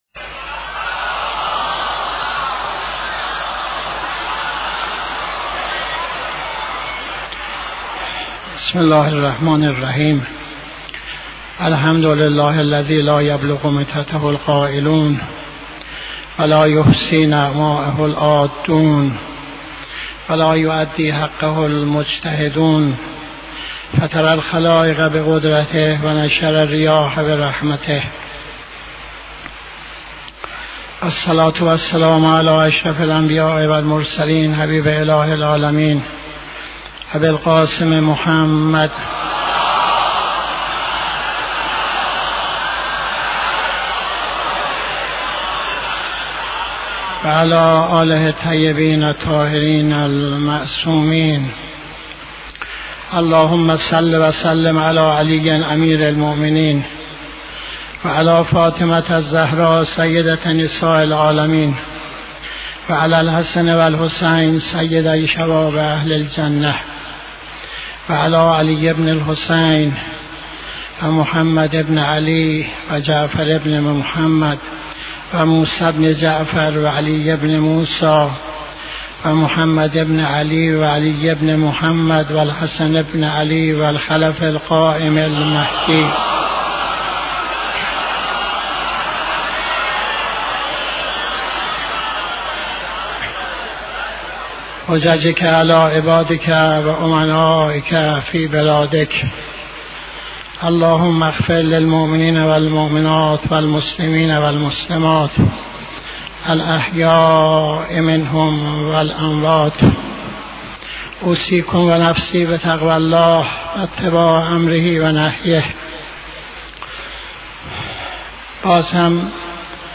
خطبه دوم نماز جمعه 12-03-85